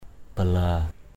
/ba-la:/ 1.